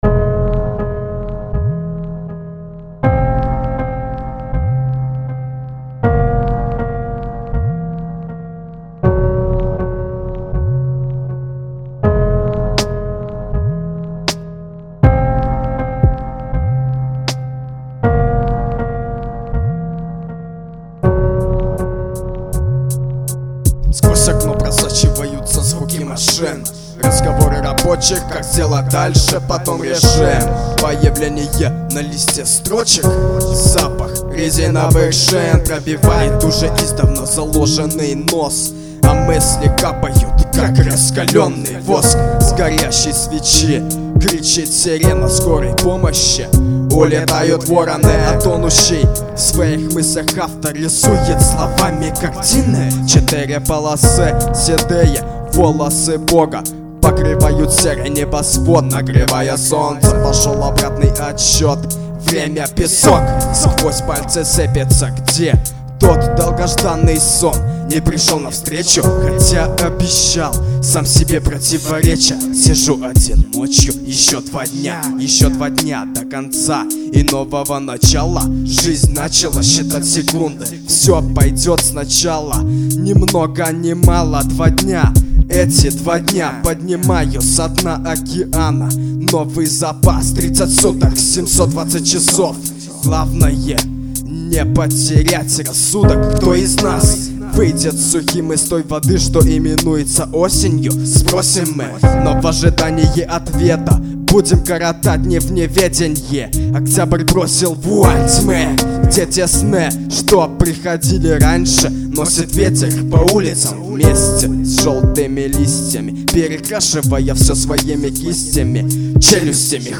Новое:, 2004/2005 Рэп Комментарии